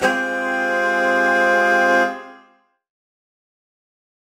UC_HornSwellAlt_Csus4min6.wav